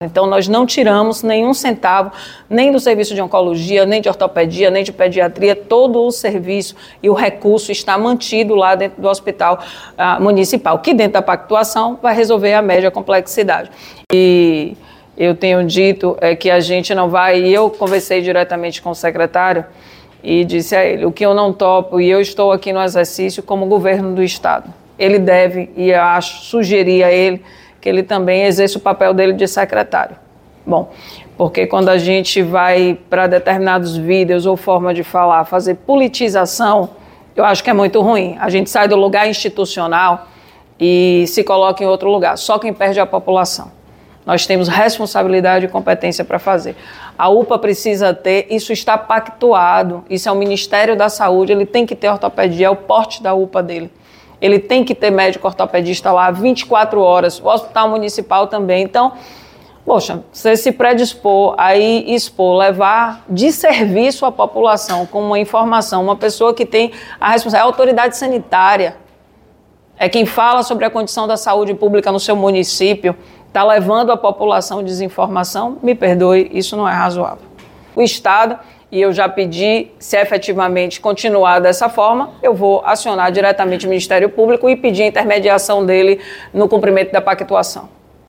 🎙Entrevista com a Secretária Roberta Santana